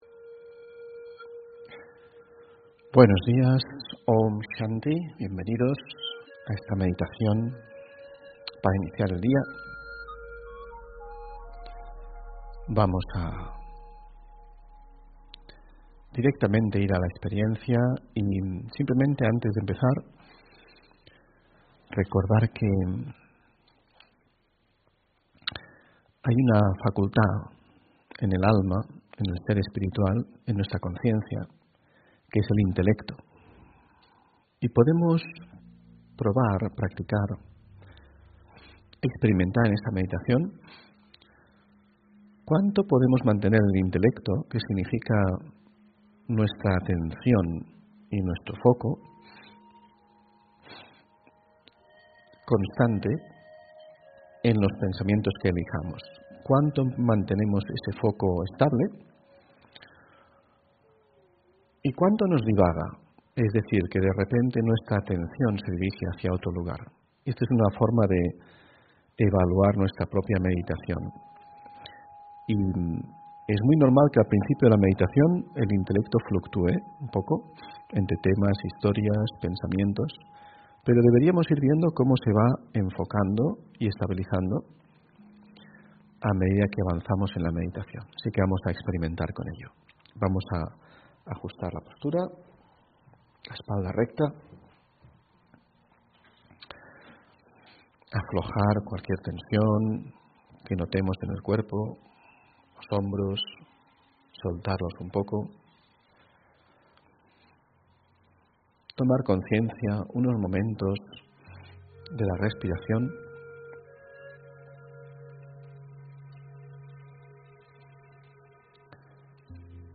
Meditación mundial por la Paz: Autorespeto y Servicio con la mente (20 Diciembre 2020)